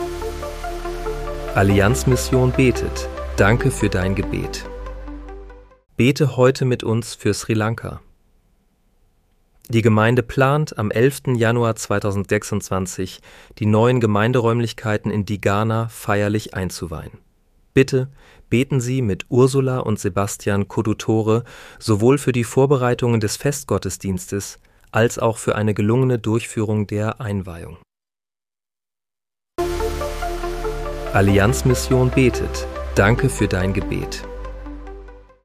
Bete am 10. Januar 2026 mit uns für Sri Lanka. (KI-generiert mit